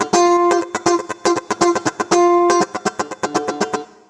120FUNKY20.wav